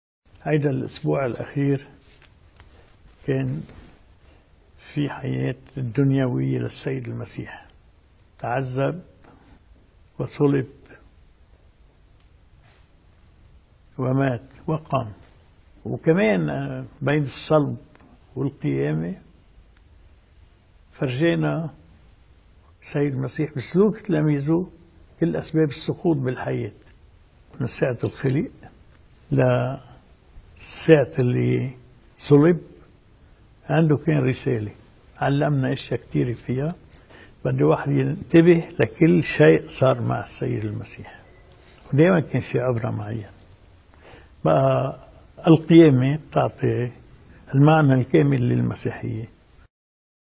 مقتطف من حديث الرئيس ميشال عون لمحطة “SAT 7” لمناسبة حلول عيد الفصح: